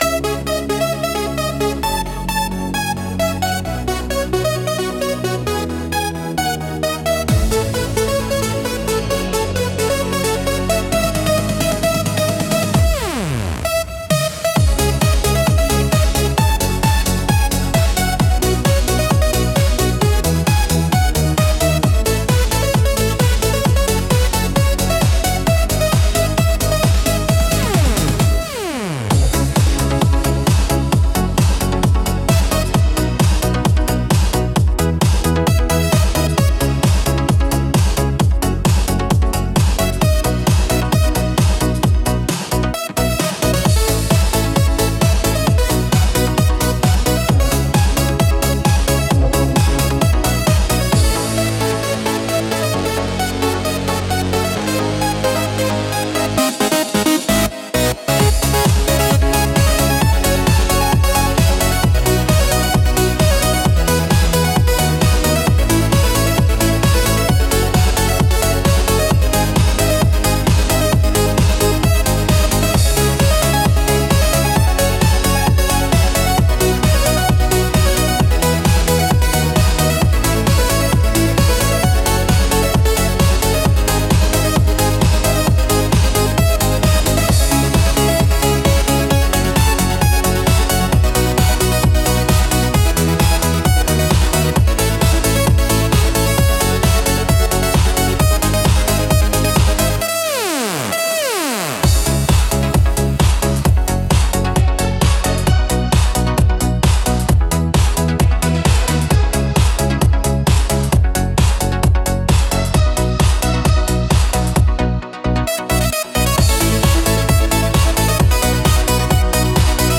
Instrumental - Bonjour, Bassline! 2.57